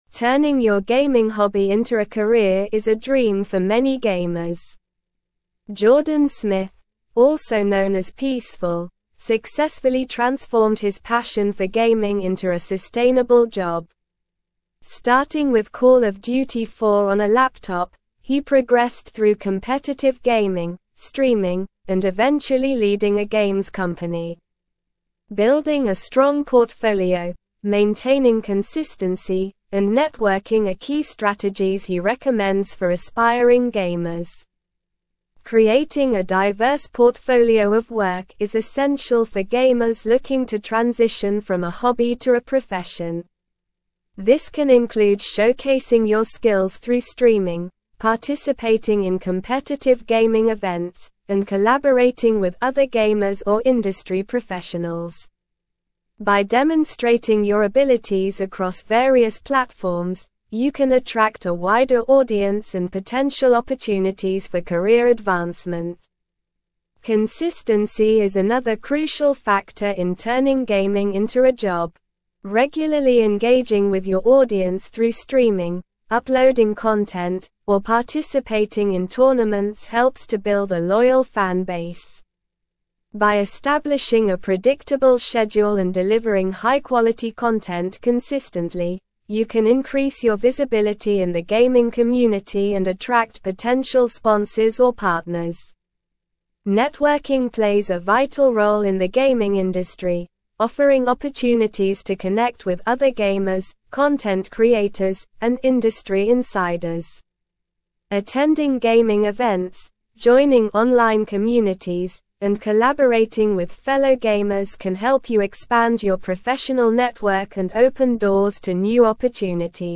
Slow
English-Newsroom-Advanced-SLOW-Reading-Turning-Gaming-Passion-into-Career-Success-Key-Strategies.mp3